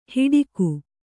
♪ hiḍiku